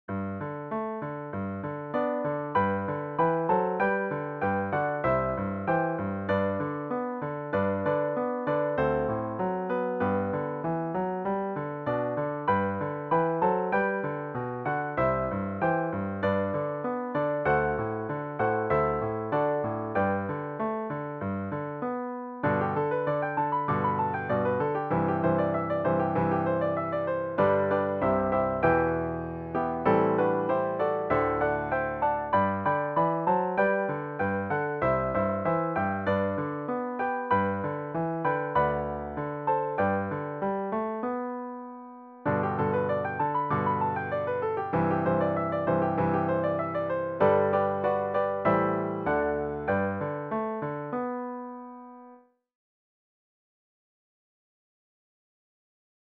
PIANO SOLO Patriotic, American Music Skill level
DIGITAL SHEET MUSIC - PIANO SOLO